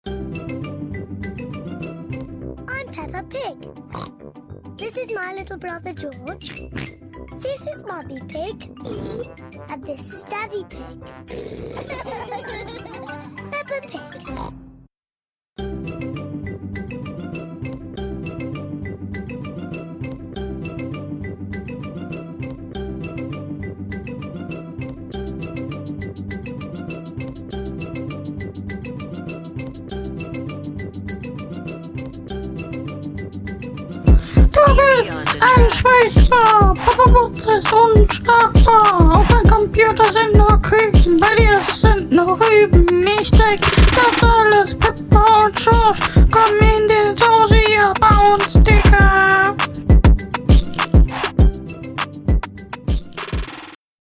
Der Beat war sehr gut dein Text war sehr gut und dein warst auch auf …